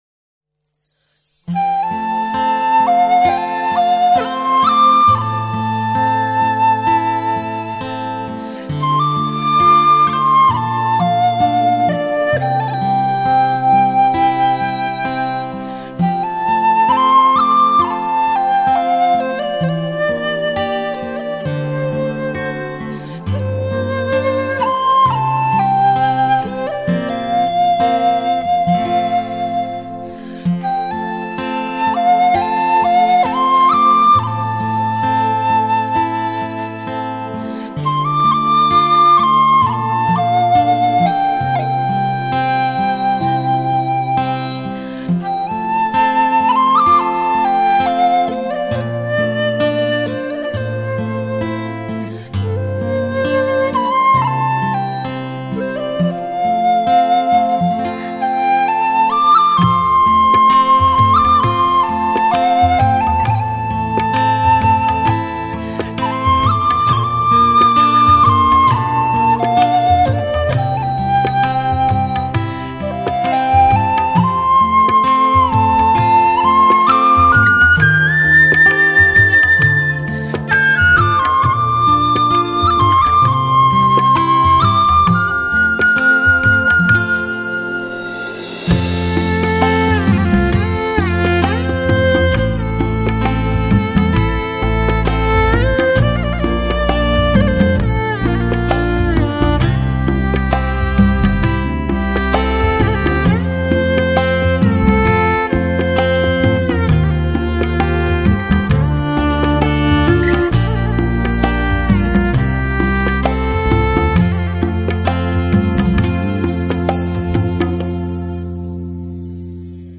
类型: HIFI试音
融合世界音乐与新世纪音乐风格
“笛、箫”跨界发烧唱片